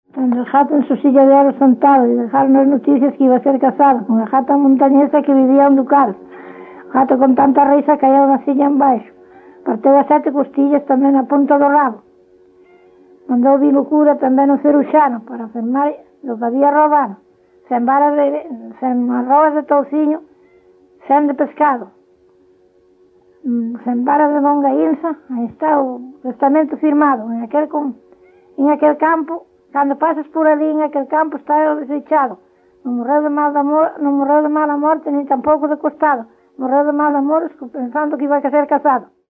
Palabras chave: gato copla de cego
Tipo de rexistro: Musical
LITERATURA E DITOS POPULARES > Cantos narrativos
Lugar de compilación: Mesía
Soporte orixinal: Casete
Instrumentación: Voz
Instrumentos: Voz feminina